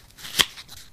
cardOpenPackage1.ogg